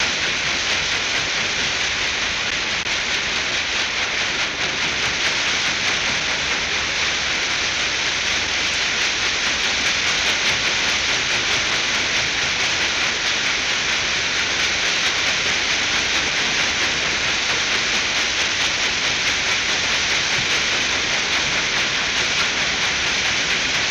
Paddle Boat Water Wash